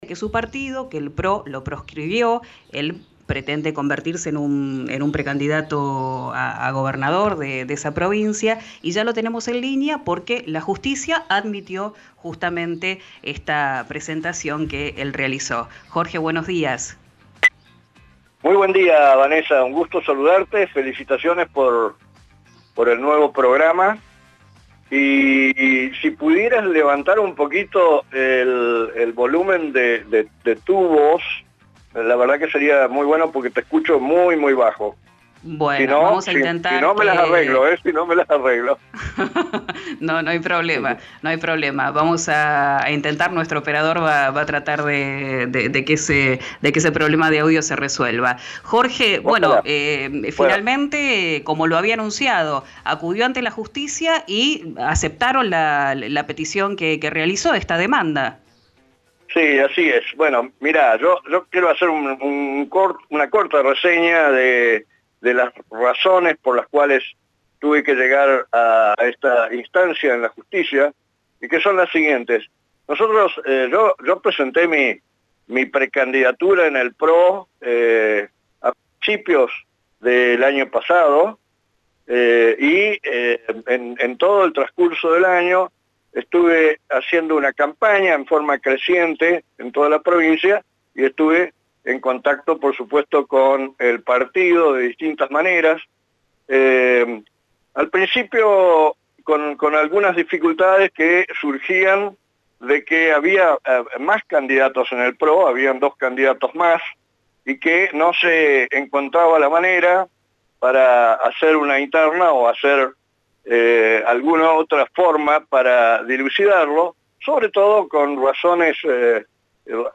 Escuchá la charla de Jorge Taylor con RÍO NEGRO RADIO.